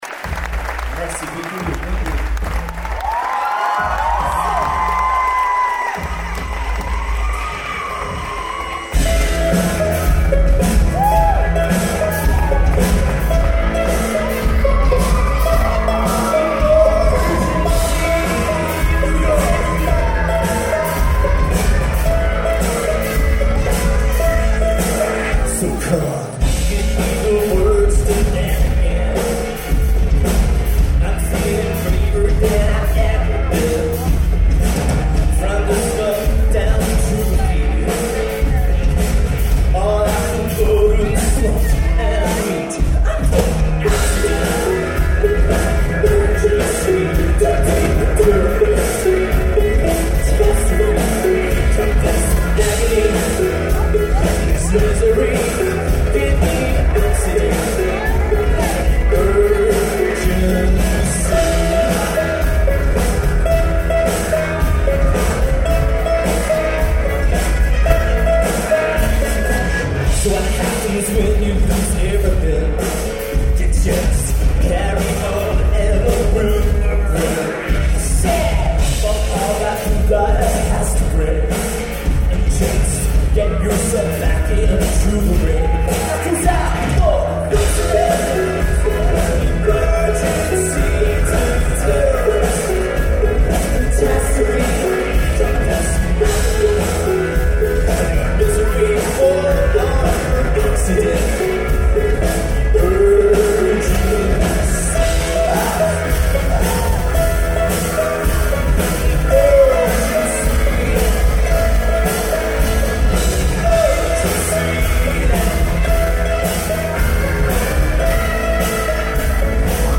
revival of ’80s UK based new wave